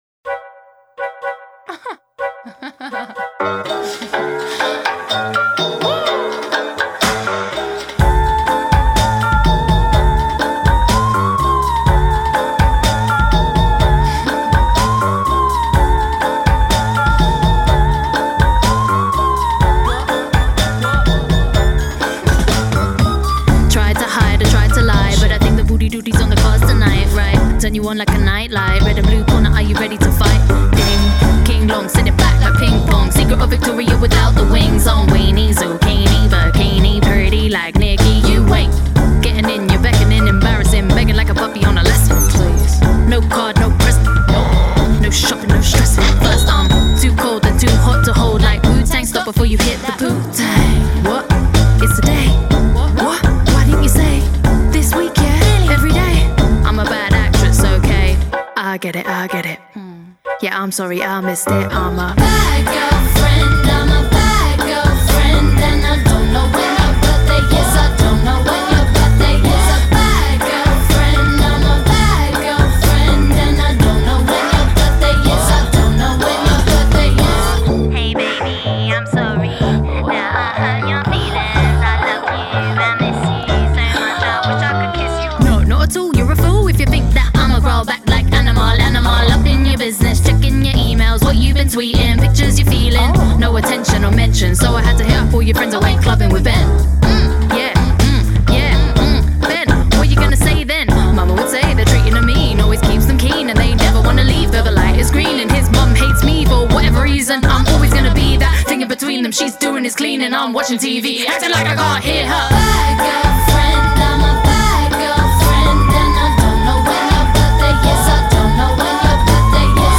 Pop, Hip Hop and Indie